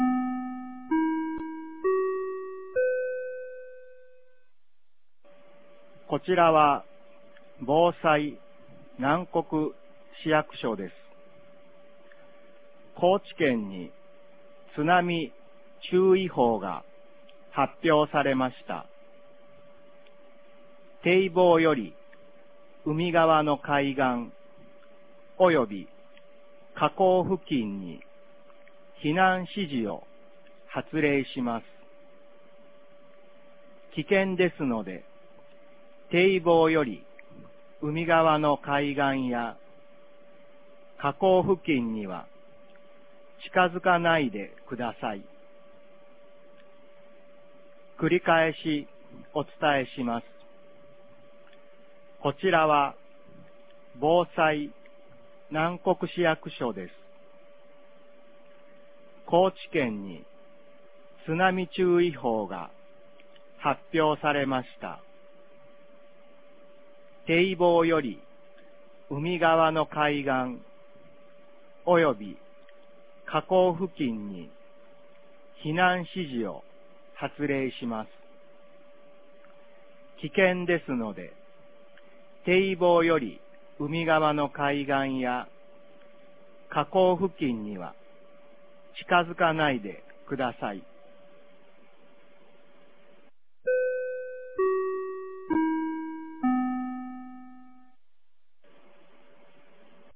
2023年10月09日 08時18分に、南国市より放送がありました。
放送音声